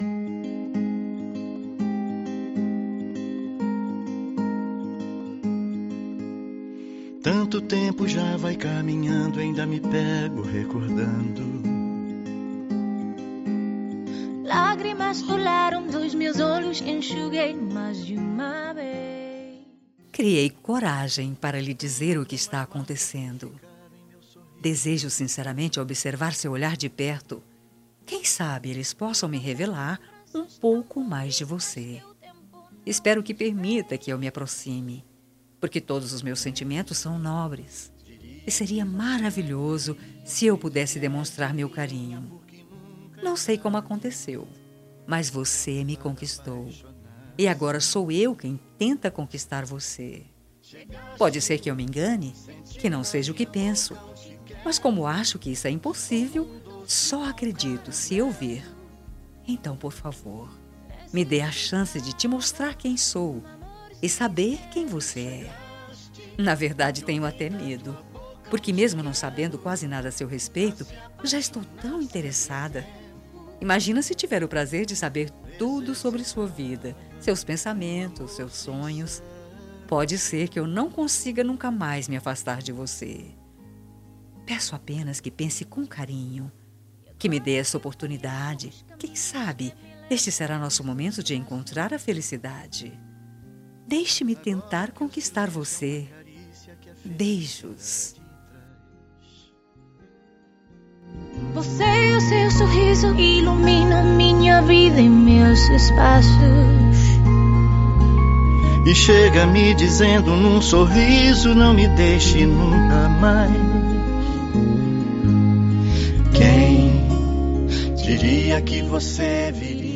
Telemensagem de Conquista – Voz Feminina – Cód: 10546